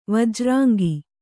♪ vajrāngi